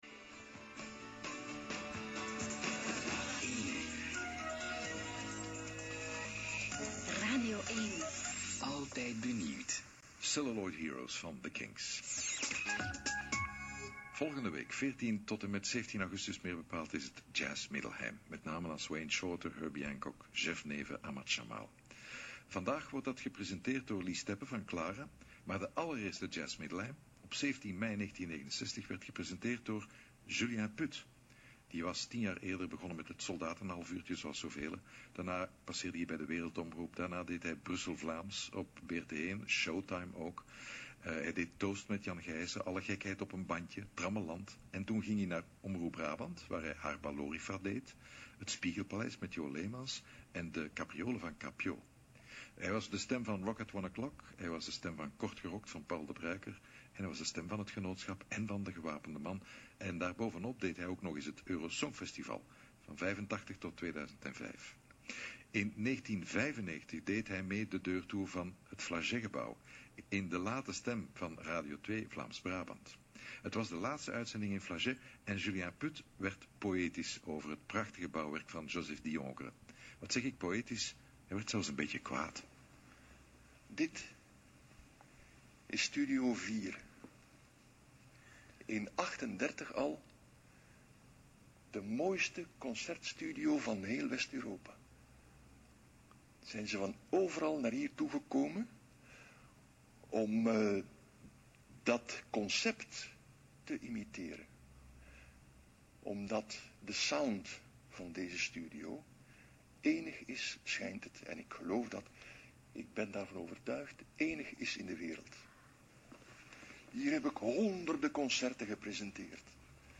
terwijl hij door het Flageygebouw wandelt.